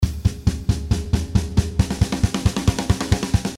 136 BPM - Lots Of Fills (26 variations)
Rock drum loops in 136 bpm. Mid tempo beat with open hihat, giving it rock style rhythm. More then 10 snare and tom fills (26 loops it total).